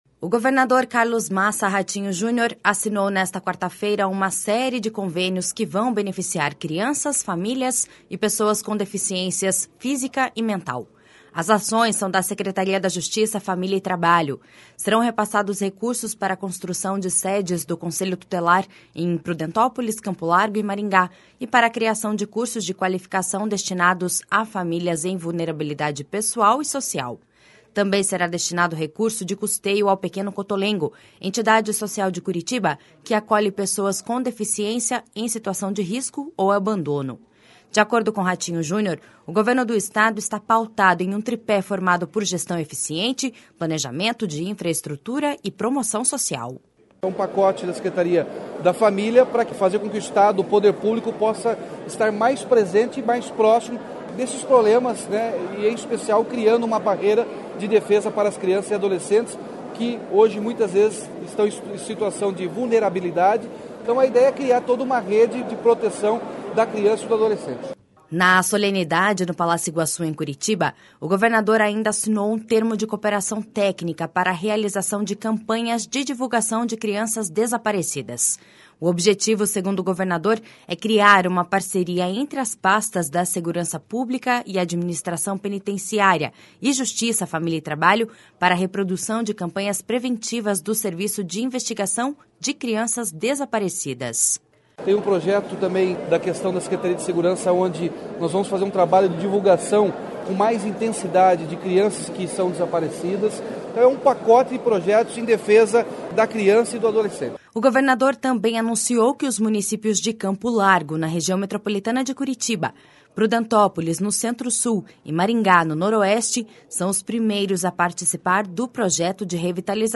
De acordo com Ratinho Junior, o Governo do Estado está pautado em um tripé formado por gestão eficiente, planejamento de infraestrutura e promoção social.// SONORA RATINHO JUNIOR.//
Na solenidade, no Palácio Iguaçu, em Curitiba, o governador ainda assinou um termo de cooperação técnica para a realização de campanhas de divulgação de crianças desaparecidas.
O secretário da Justiça, Família e Trabalho, Ney Leprevost, garantiu que o governo vai se esforçar para melhorar o atendimento nos conselhos tutelares de todo o Paraná.// SONORA NEY LEPREVOST.//